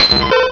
-Replaced the Gen. 1 to 3 cries with BW2 rips.